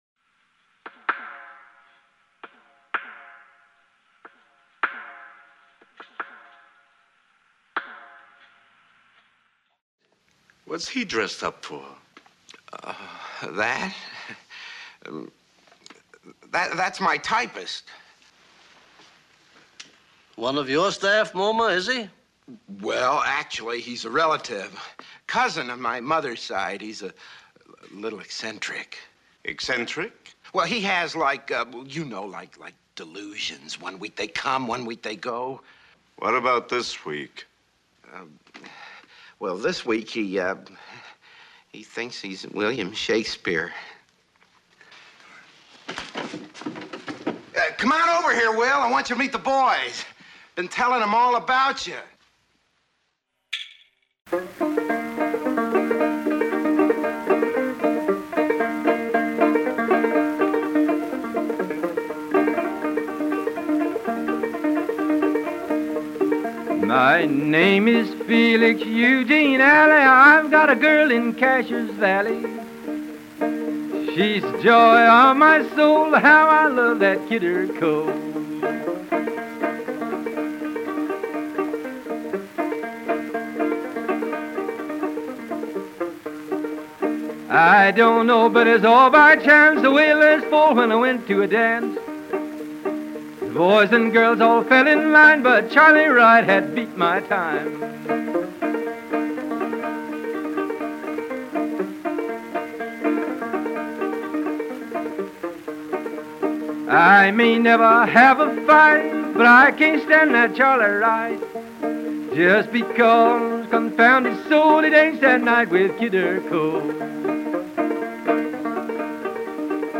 Muddy Boots wanders where sneakers never will, moving on the back roads through the backwoods and to the forgotten cracks of our imagination. Tune in to hear a patchwork of sound, snippet, and song; field recordings from wherever, the random whimsical, and the rare, and not-so-rare.